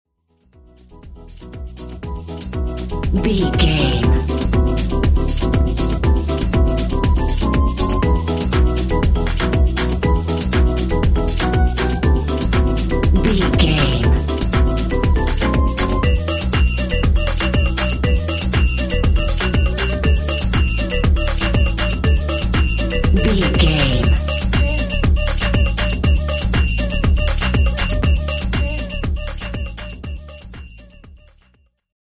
Lift Music Theme.
Aeolian/Minor
SEAMLESS LOOPING?
Drum and bass
break beat
electronic
sub bass
drums
synth